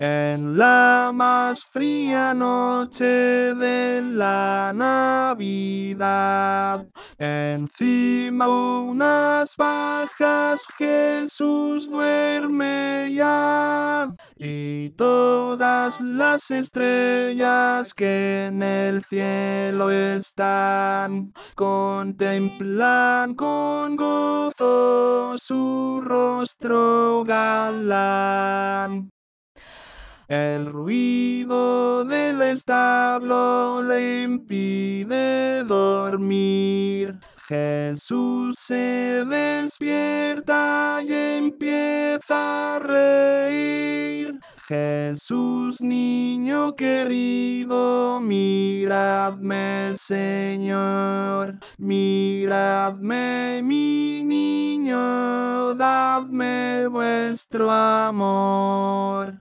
Para aprender la melodía os dejo estos MIDIS, con la voz principal destacada por encima del resto.
en-la-mas-fria-noche-tenores.mp3